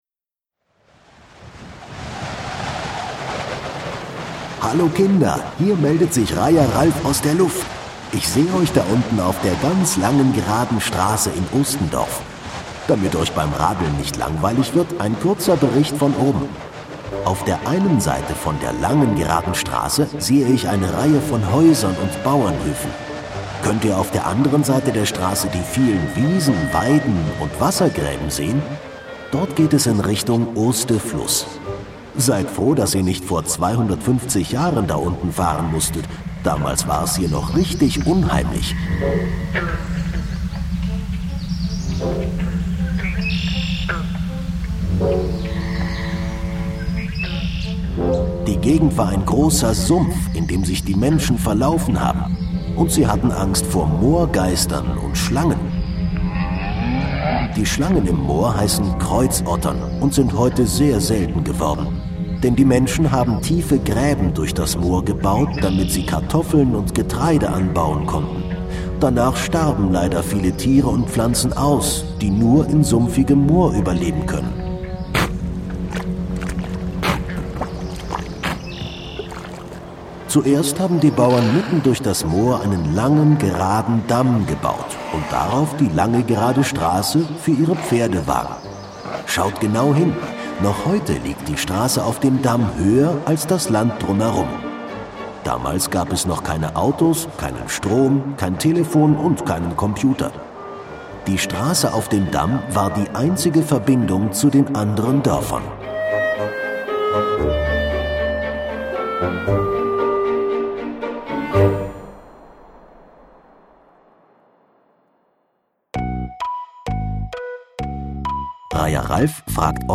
Moorhufendorf Ostendorf - Kinderaudioguide Oste-Natur-Navi